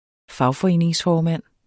Udtale [ ˈfɑwfʌˌeˀneŋs- ]